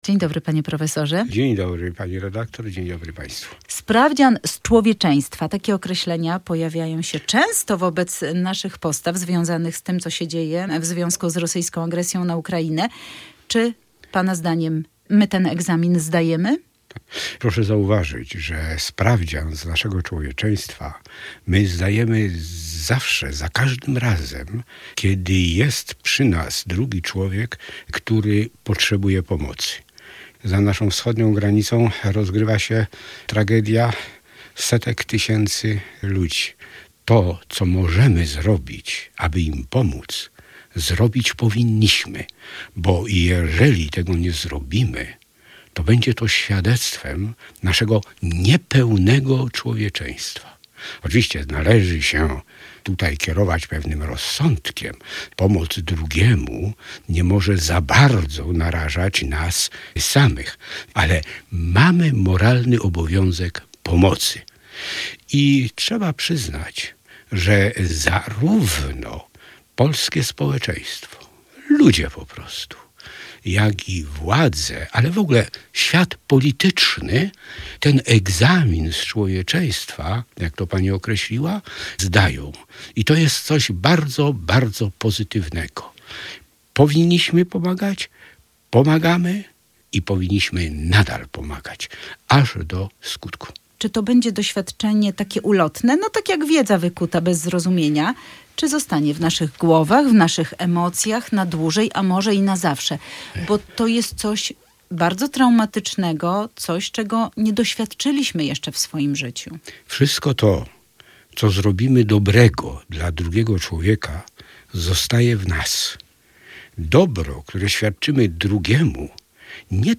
etyk